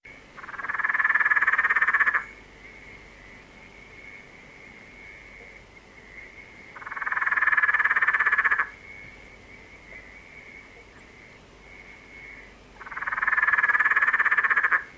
This hideout of the frogs between pipes was an ideal opportunity to show some of their identifying features, such as the tympanum (ear), the foot-webbing and digit pads, the eye pupil etc. The Peron’s Tree Frog, for instance, is immediately recognisable by its cross-shaped pupil, if not from its weird call.
Peron’s Tree Frog –
perons-tree-frog.mp3